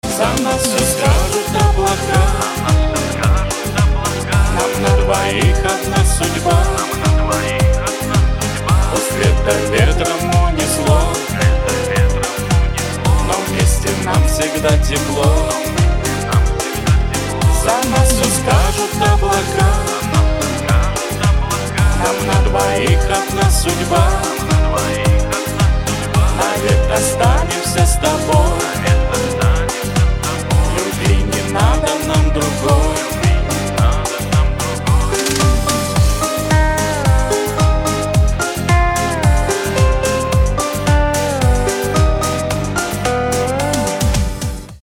• Качество: 256, Stereo
мужской вокал
женский вокал
русский шансон